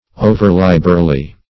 overliberally - definition of overliberally - synonyms, pronunciation, spelling from Free Dictionary Search Result for " overliberally" : The Collaborative International Dictionary of English v.0.48: Overliberally \O"ver*lib"er*al*ly\, adv.